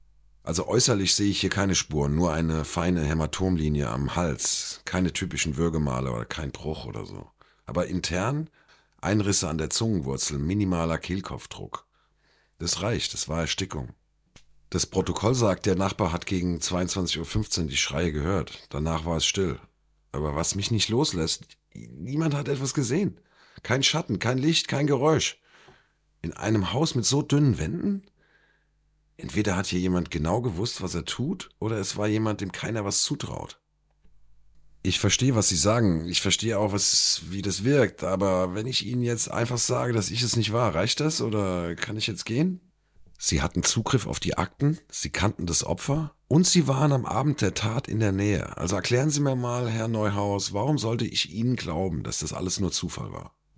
Male
My voice ranges from warm and deep to calm and engaging, making it appropriate for a variety of projects.
Movie Trailers
German Dubbing Mix